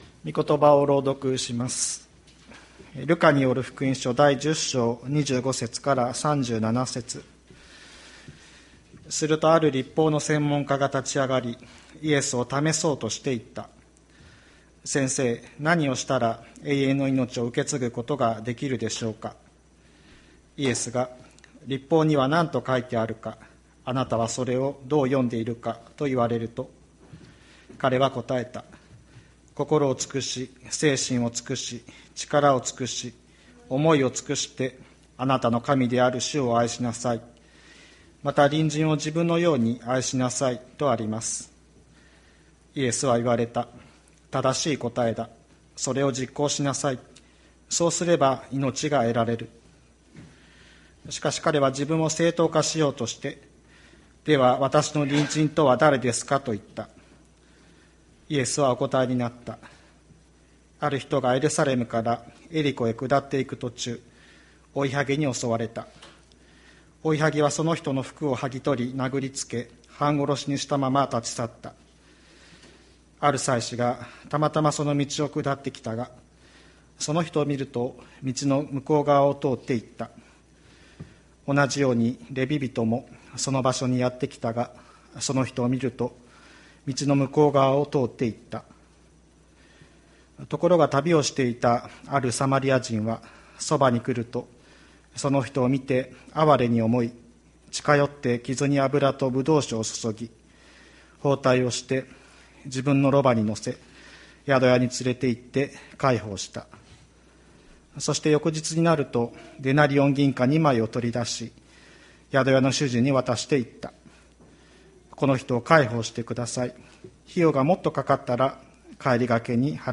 礼拝説教 日曜朝の礼拝